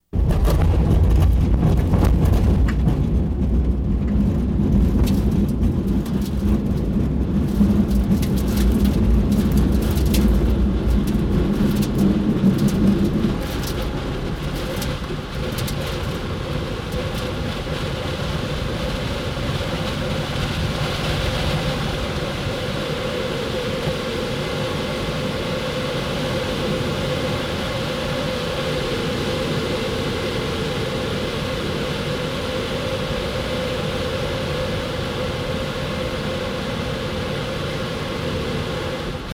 Glider Take Off Mono, Loop In Flight